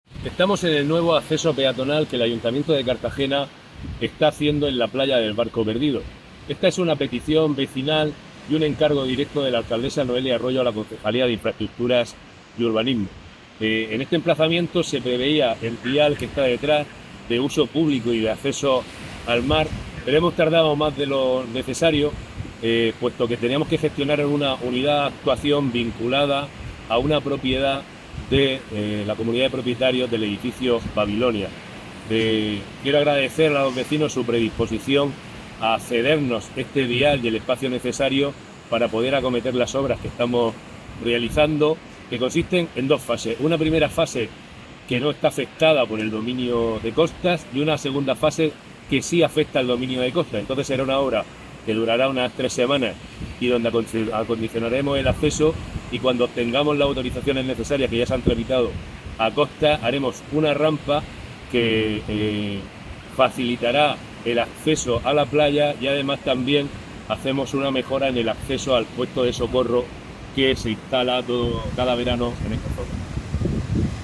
Enlace a Declaraciones de Diego Ortega.